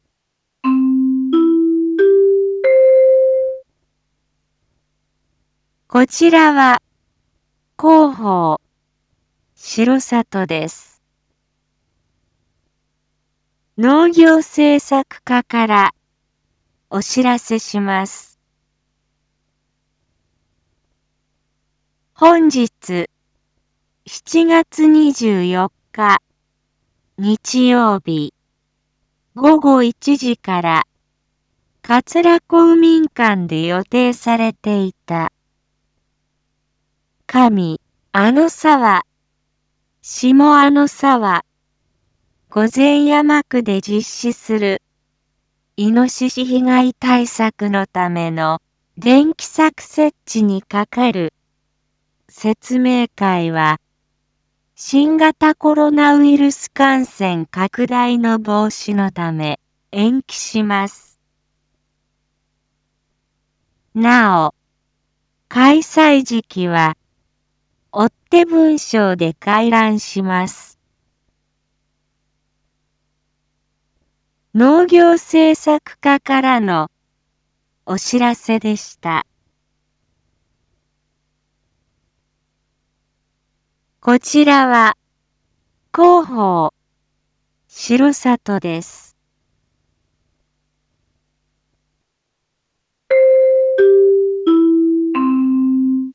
一般放送情報
Back Home 一般放送情報 音声放送 再生 一般放送情報 登録日時：2022-07-24 07:01:37 タイトル：R4.7.24 7時放送分 インフォメーション：こちらは広報しろさとです。